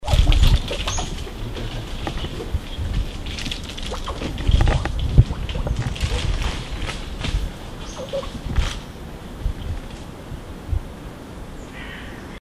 The short, soft "chukking" call of Red squirrels during a chase.